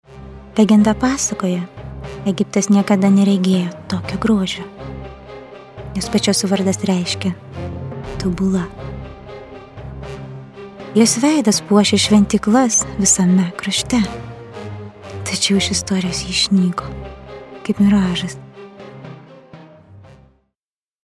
slightly deep, smooth, alluring, safe, passionate and with calming influence voice
Sprechprobe: Sonstiges (Muttersprache):